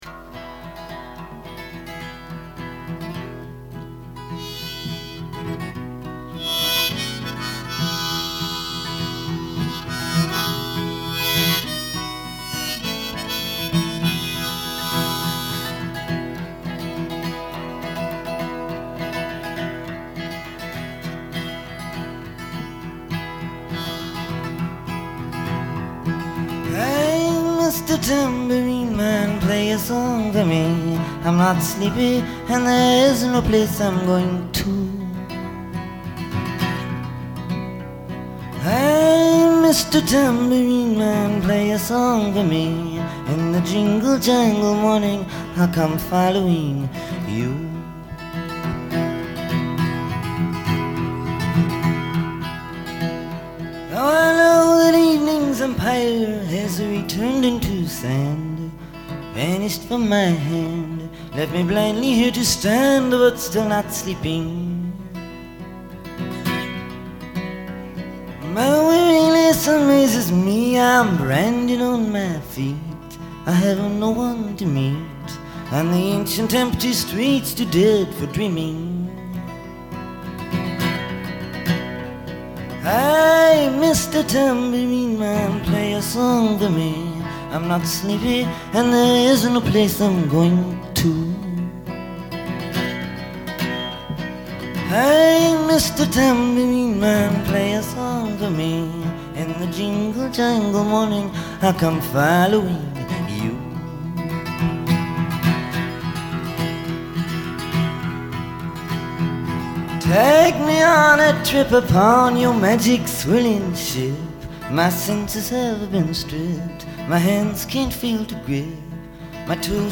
closing harmonica solo